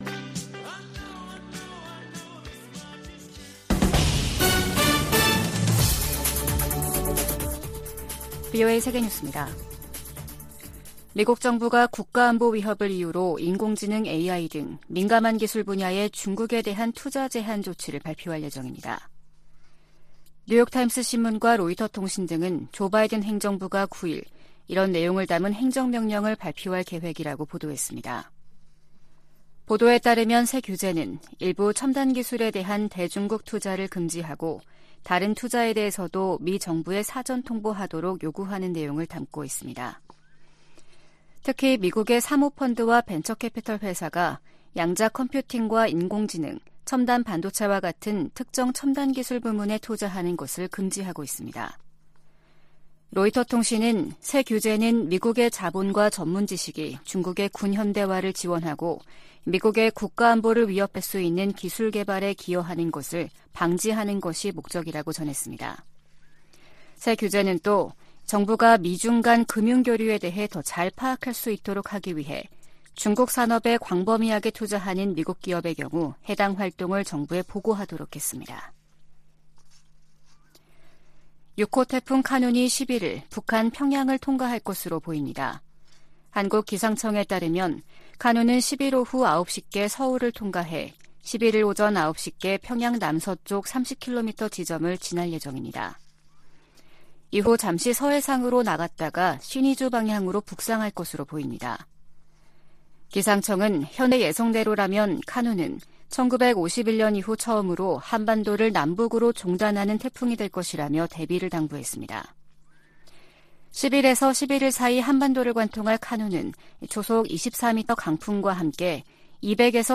VOA 한국어 아침 뉴스 프로그램 '워싱턴 뉴스 광장' 2023년 8월10일 방송입니다. 미 국방부는 북한과의 무기 거래는 불법이라며 거기에는 대가가 따를 것이라고 경고했습니다. 중국 중고 선박이 북한으로 판매되는 사례가 잇따르고 있는 가운데 국무부는 기존 대북제재를 계속 이행할 것이라는 입장을 밝혔습니다. 다음 주 미국에서 열리는 미한일 정상회의를 계기로 3국 정상회의가 정례화될 가능성이 높다고 미국 전문가들이 전망했습니다.